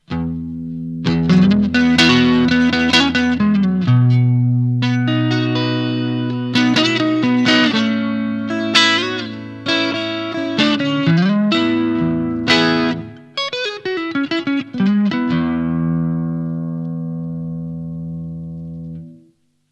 Par rapport à une Tube Screamer standard, la Custom Screamer peut fournir plus de gain. Elle laisse aussi passer davantage de grave et de bas médium. Elle délivre également un SON plus CLEAN quand le contrôle GAIN/DRIVE est à zéro et peut donc servir de booster.